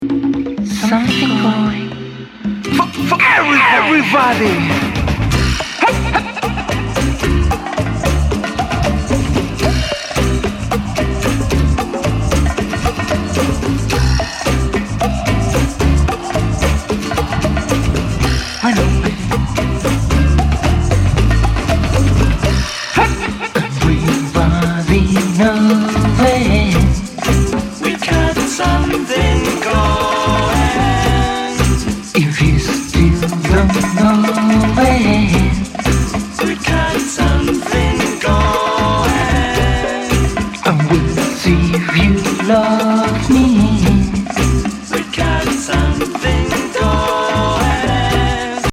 ヘビー・サイケ!?素晴らしすぎ!!